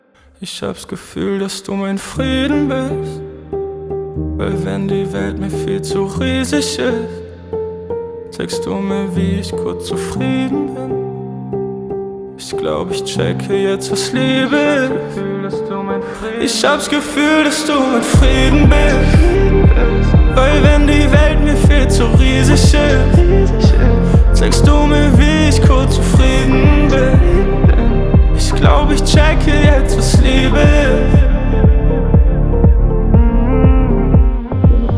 2025-07-04 Жанр: Поп музыка Длительность